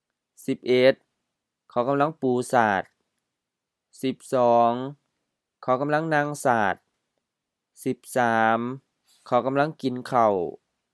เขา khao M เขา personal pronoun: he, she
กำลัง gam-laŋ M-HR กำลัง auxiliary indicating continuous or progressive action
ปู pu: M ปู to lay, to spread, to unfold
สาด sa:t LF เสื่อ mat
นั่ง naŋ H นั่ง to sit
กิน gin M กิน to eat, to consume, to use